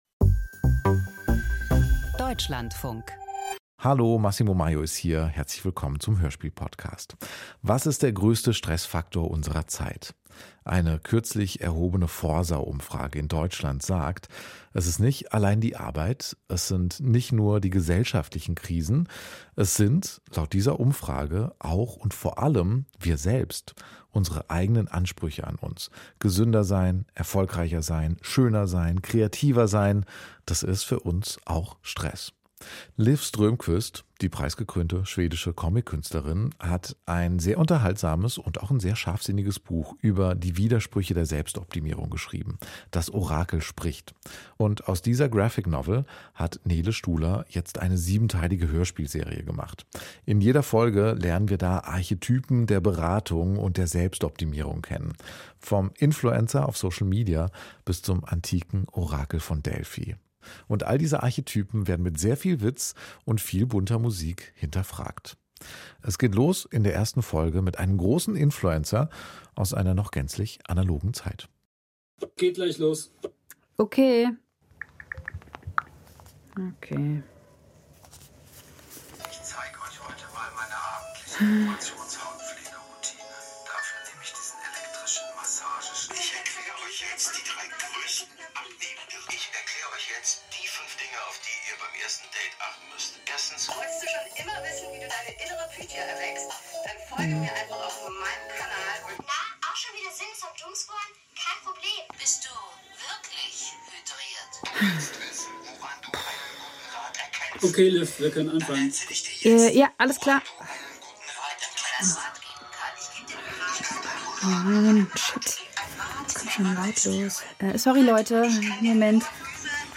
Das Orakel spricht – Hörspielserie nach Liv Strömquist